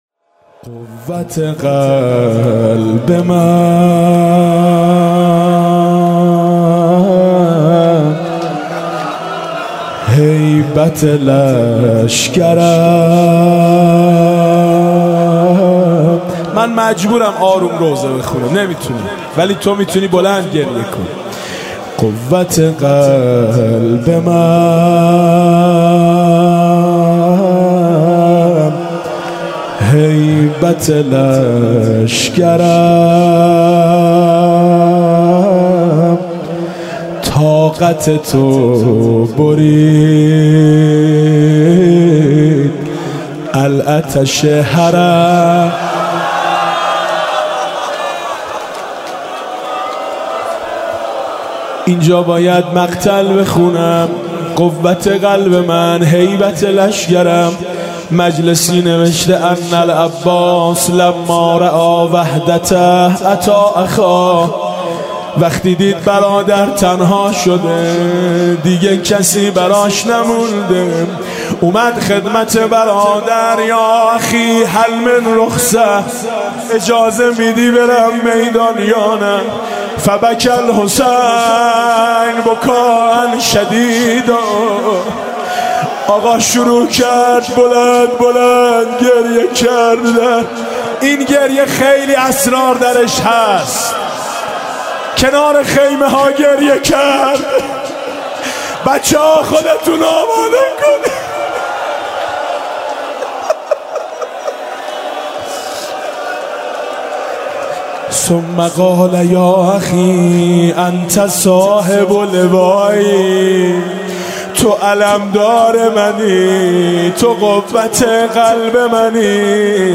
مداحی شب تاسوعا میثم مطیعی قوت قلب من، هیبت لشکرم